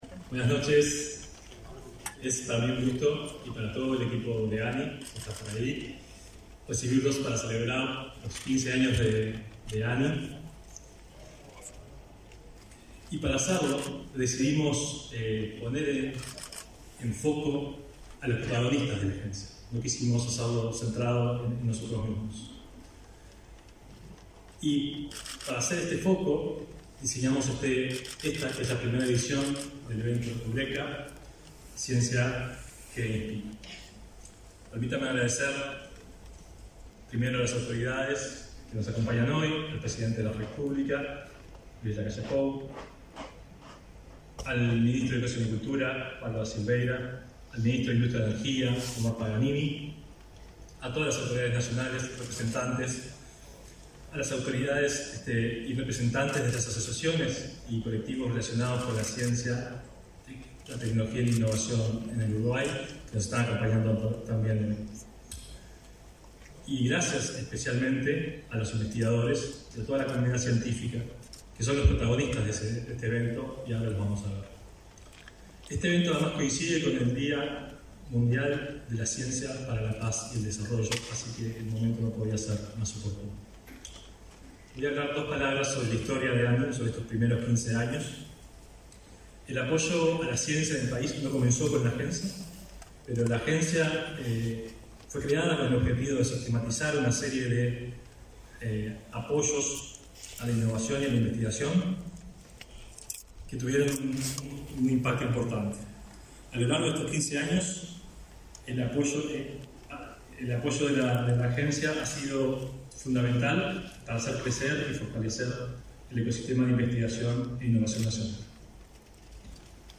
Palabras del presidente de la ANII, Flavio Caiafa
Palabras del presidente de la ANII, Flavio Caiafa 10/11/2022 Compartir Facebook X Copiar enlace WhatsApp LinkedIn En el marco de la celebración de los 15 años de la Agencia Nacional de Investigación e Innovación (ANII), y con motivo del Día Mundial de la Ciencia, se realizó, este 10 de noviembre, el evento Eureka, con la presencia del presidente de la República, Luis Lacalle Pou. El inicio del evento fue realizado por el titular de la ANII, Flavio Caiafa